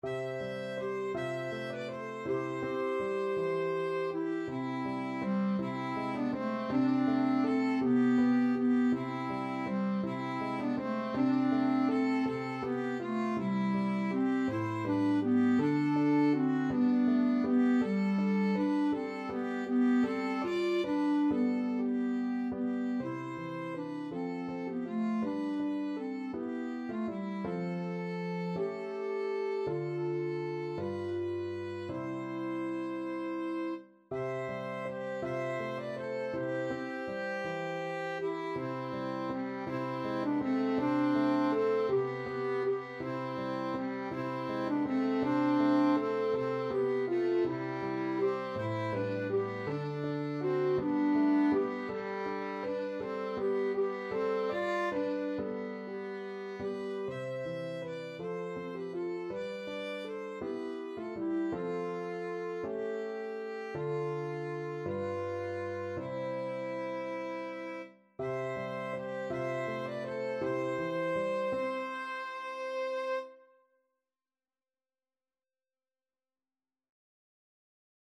ViolinClarinetPiano
6/8 (View more 6/8 Music)
Classical (View more Classical Clarinet-Violin Duet Music)